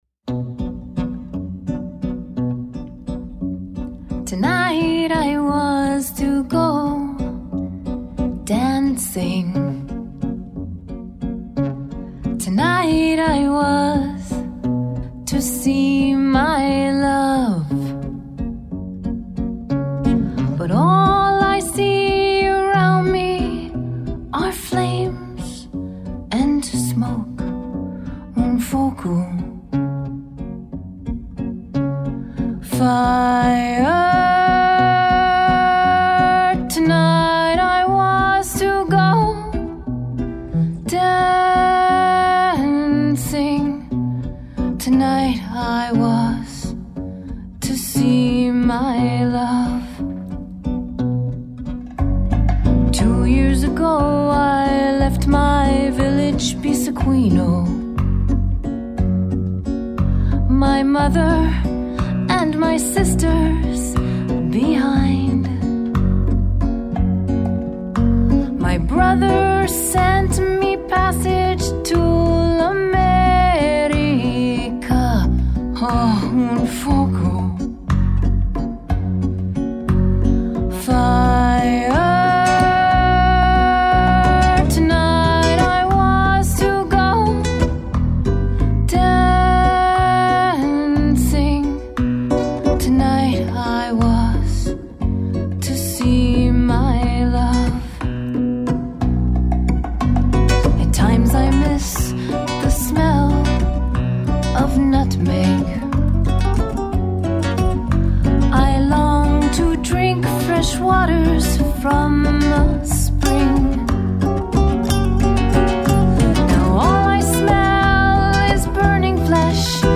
vocals
guitar
electric bass
percussion
button accordion